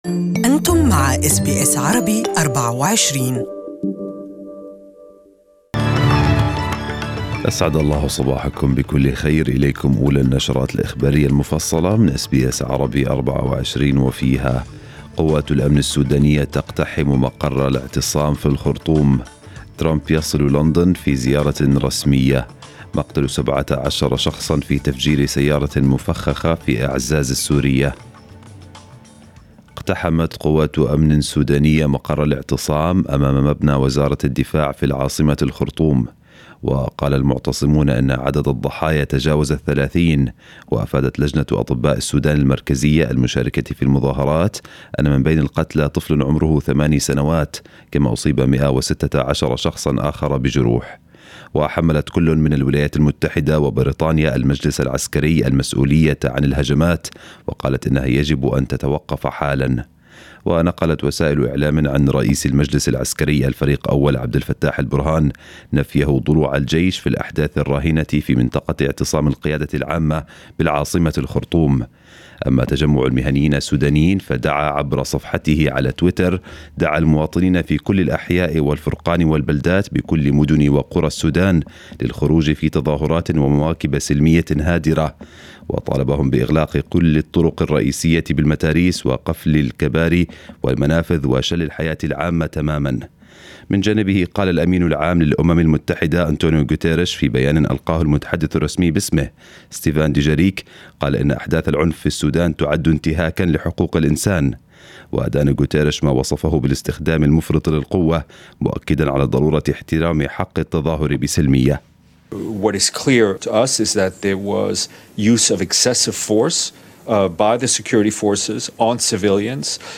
Morning News bulletin in Arabic